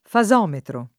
fasometro [ fa @0 metro ] s. m. (fis.)